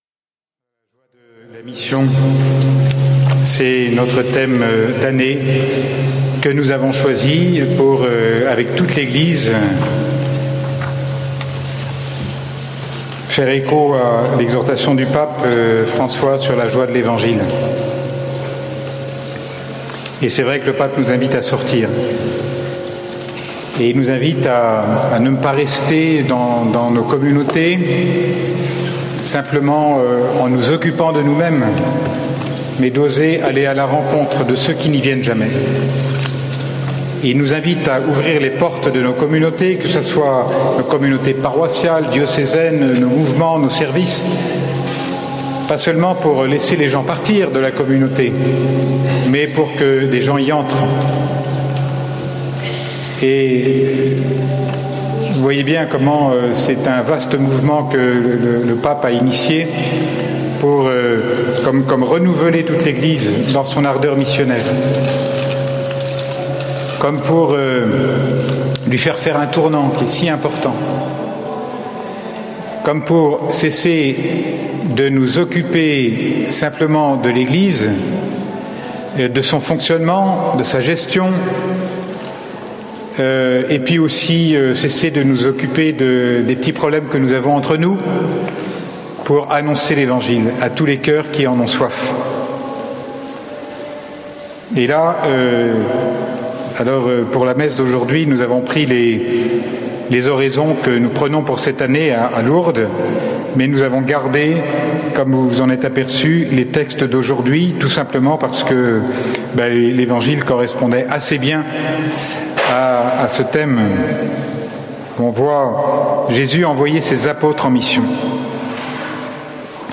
Enregistré en 2015 (Session Béatitudes)
Format :MP3 64Kbps Mono
01. (21:50mn Mgr Nicolas Brouwet) Lourdes 2015 - Homélie 1/4 (1.50 EUR)